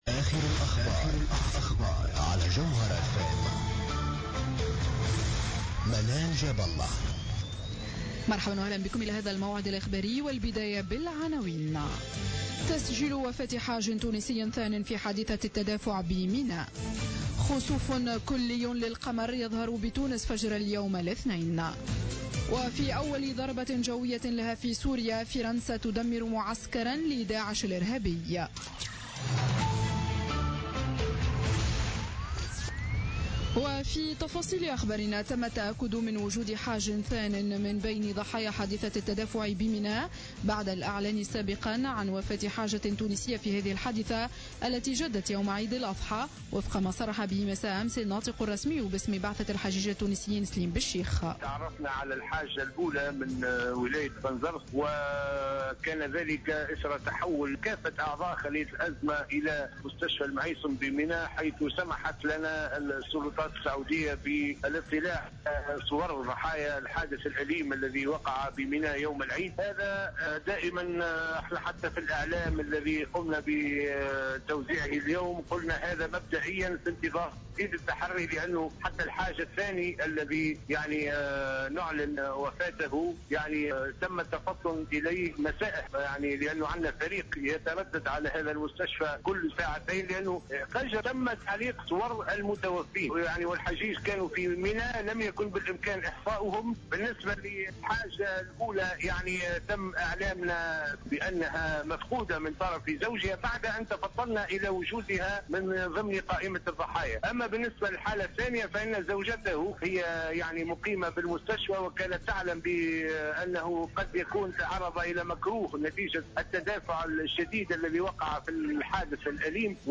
نشرة أخبار منتصف الليل ليوم الإثنين 28 سبتمبر 2015